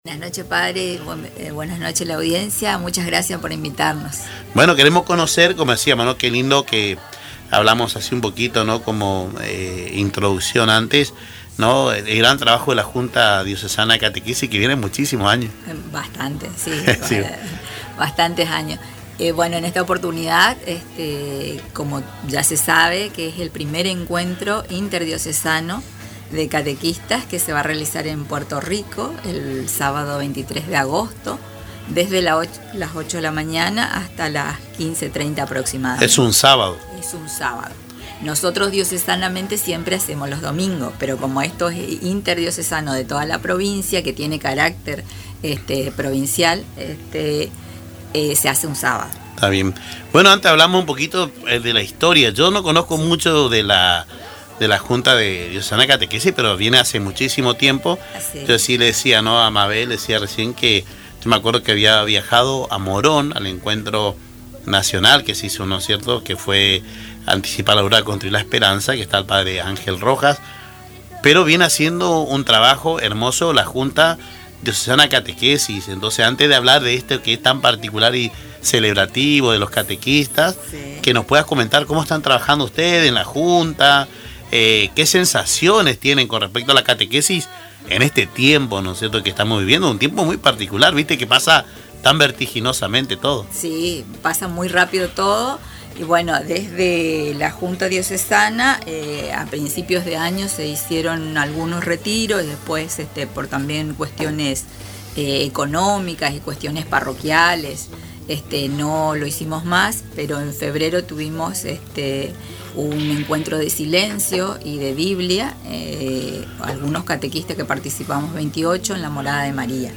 durante su intervención en el programa Caminando Juntos por Radio Tupambaé.